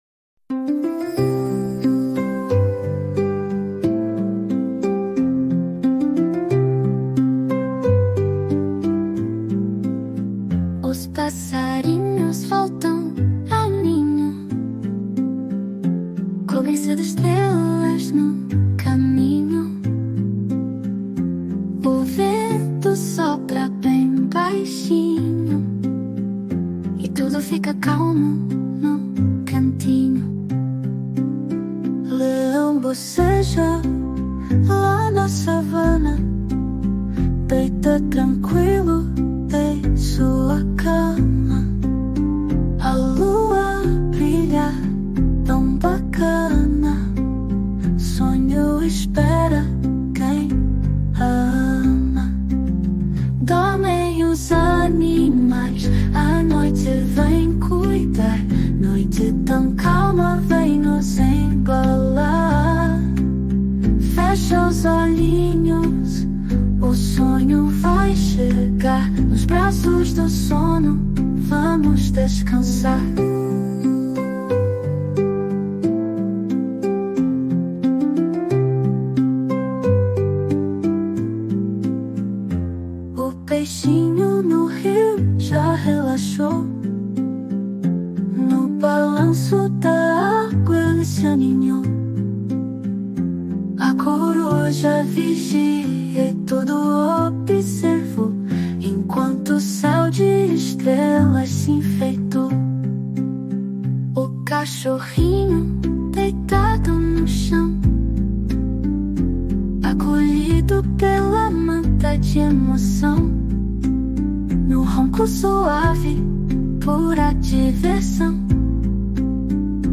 Músicas Infantis